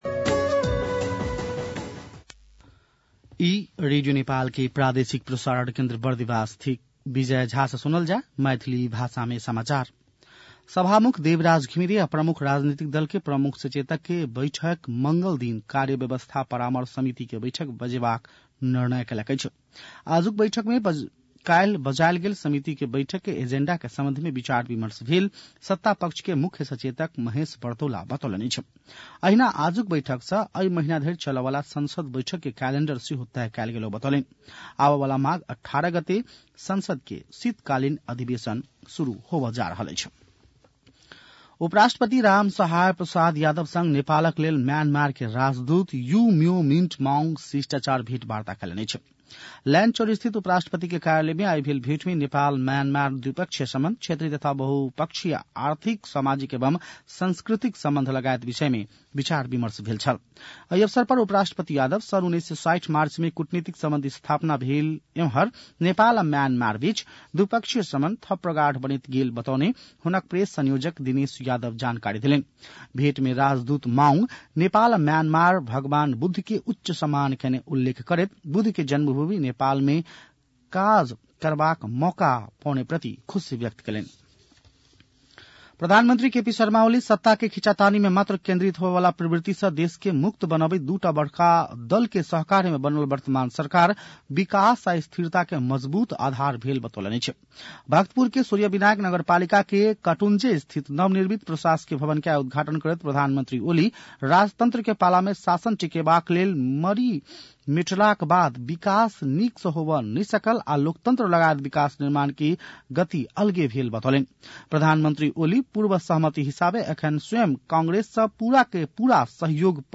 मैथिली भाषामा समाचार : १५ माघ , २०८१
Maithali-news-10-14.mp3